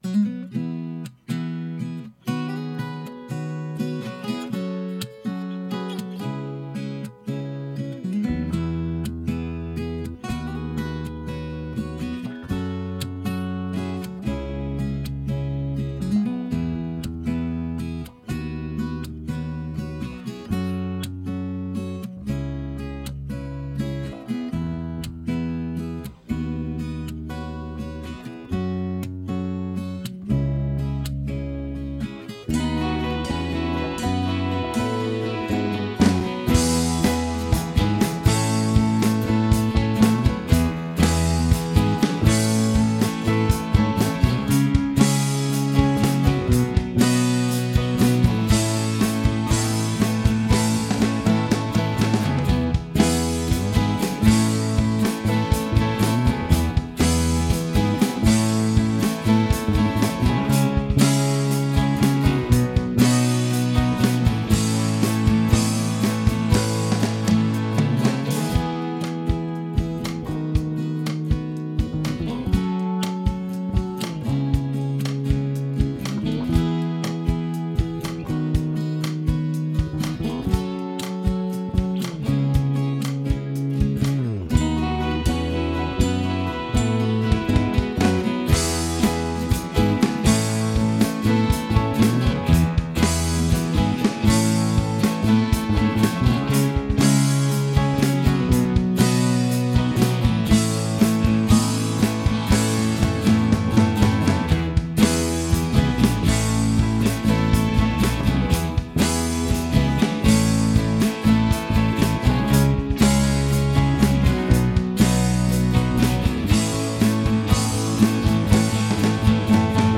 Key of E - Track Only - No Vocal